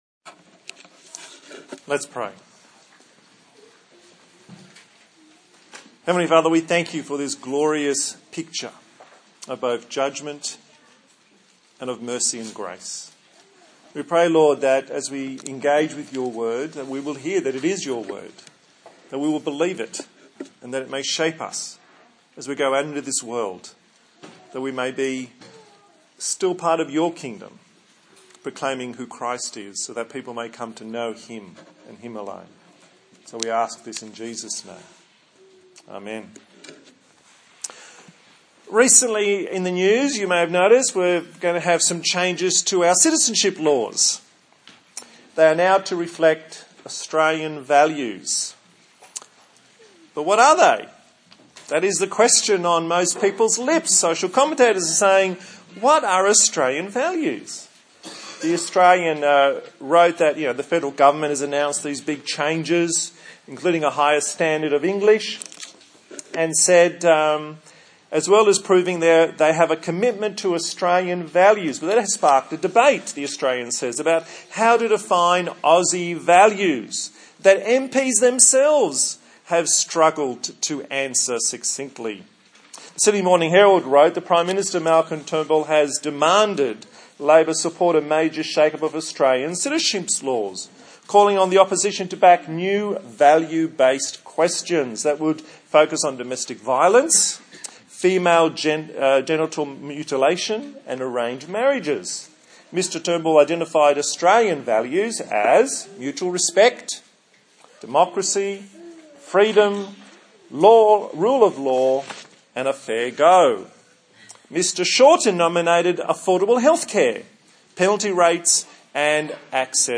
A sermon on the book of Revelation
Service Type: Sunday Morning